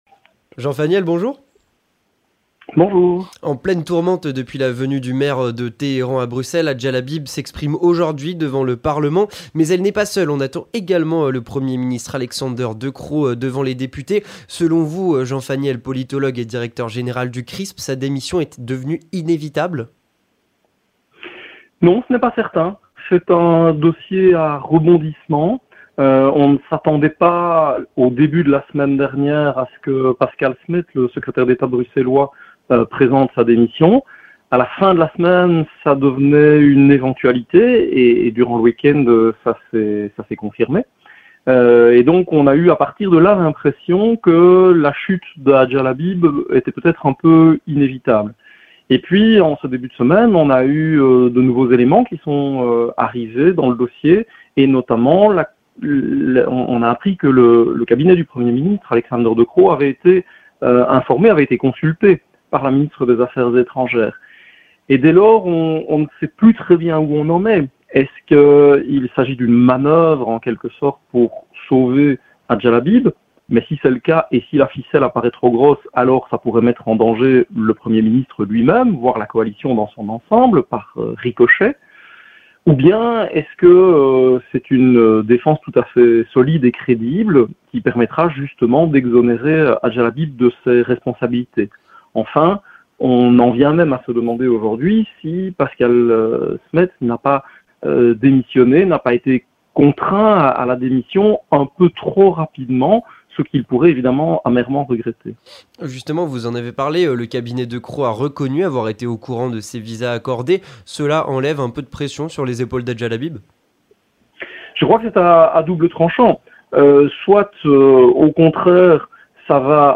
Entretien du 18h - Hadja Lahbib face aux députés du Parlement